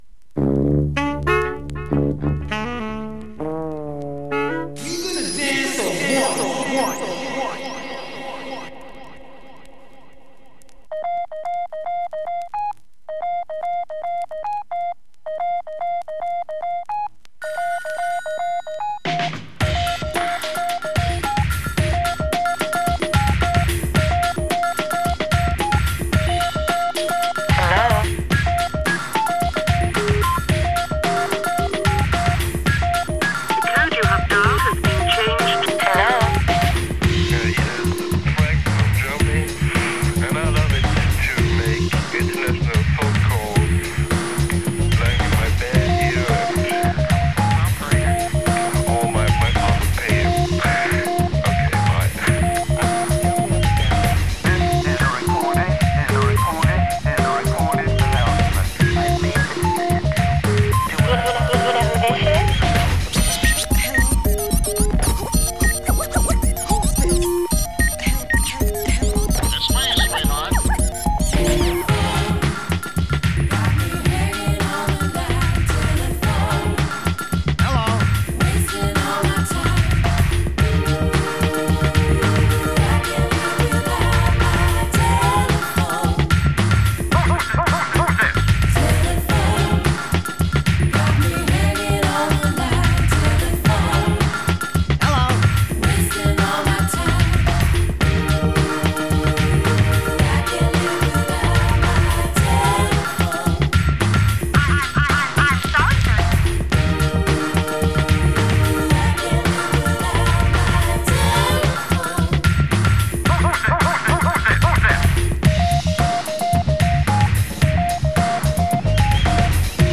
◇薄いスリキズでチリつく箇所あります